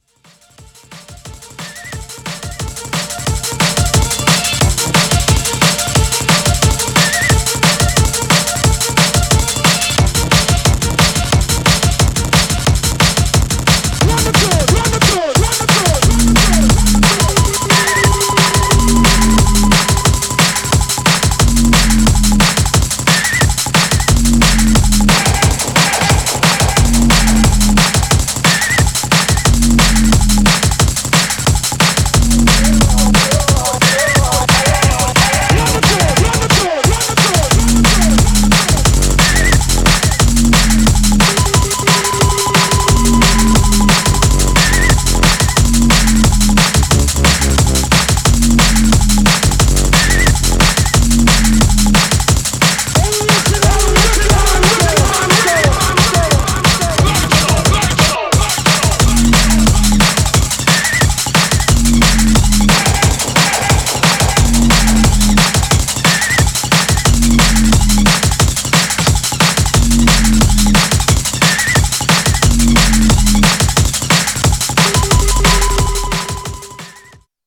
Styl: Drum'n'bass